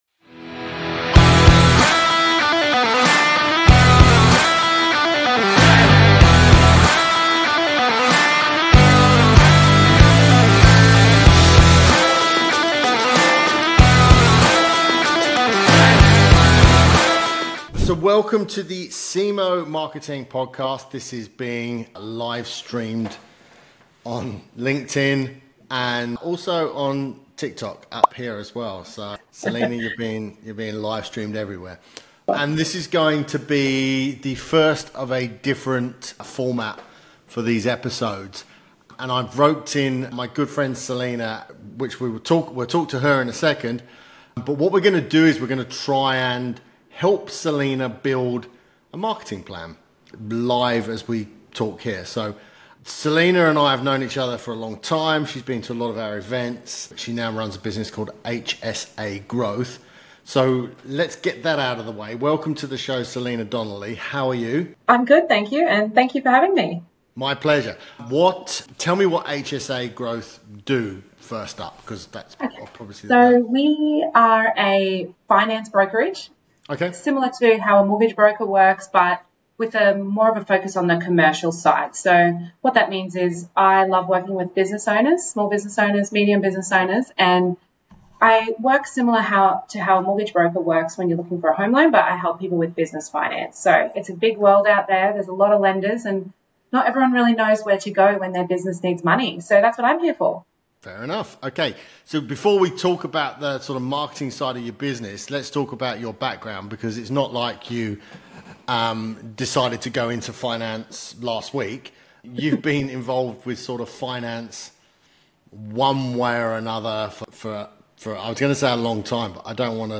The Cemoh Marketing Podcast is a marketing, entrepreneurship and business podcast dedicated to interviewing high performing CEOs, business leaders, entrepreneurs and marketing experts.